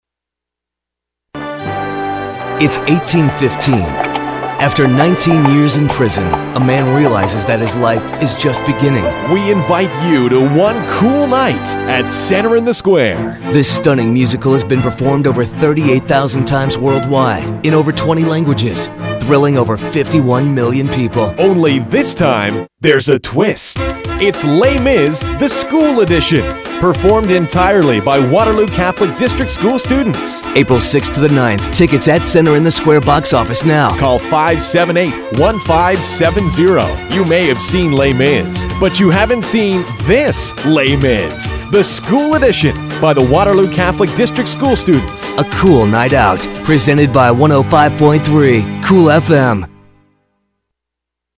This spot will be running on KOOL-FM, 105.3.